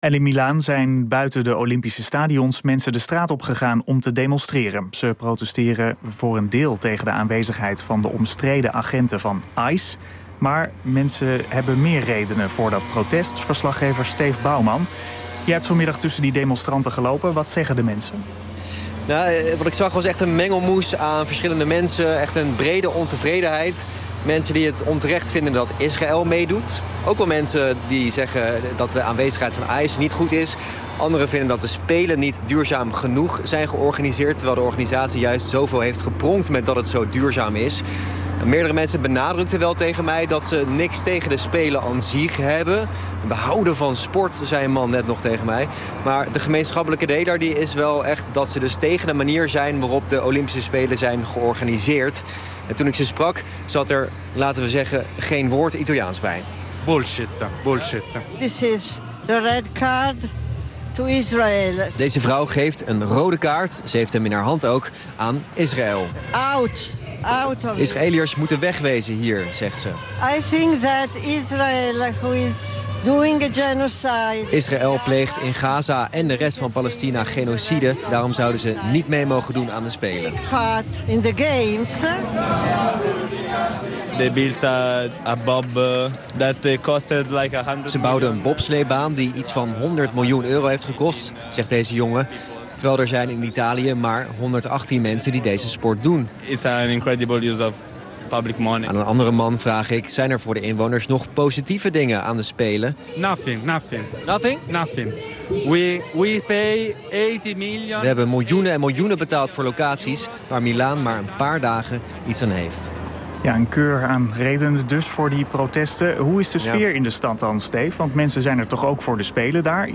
Live in een NPO Radio 1-nieuwsbulletin verslag van een demonstratie in Milaan tegen de Winterspelen: